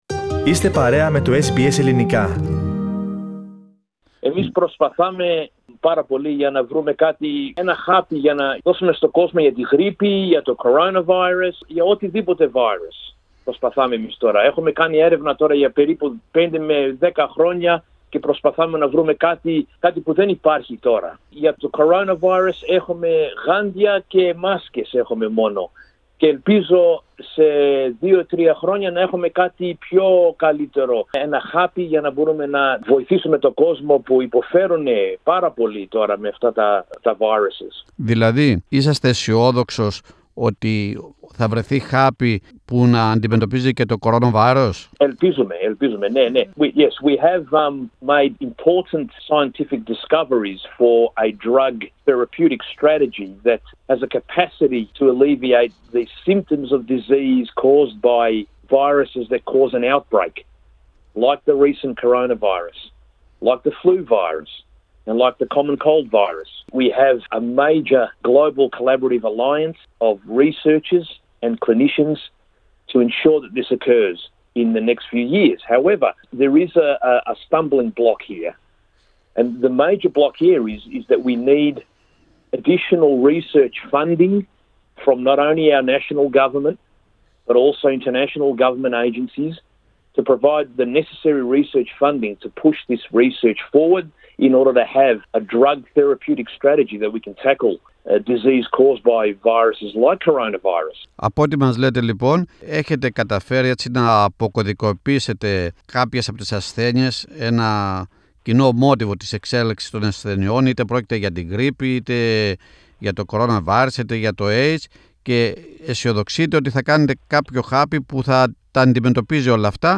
Πατήστε play στην κεντρική φωτογραφία για να ακούσετε το podcast με ολόκληρη τη συνέντευξη.